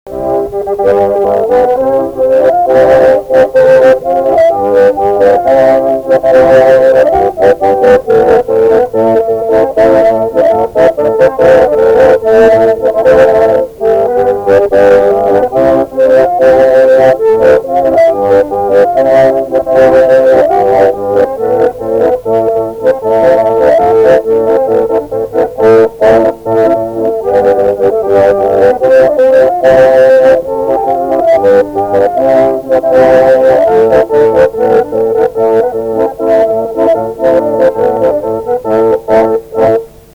instrumentinis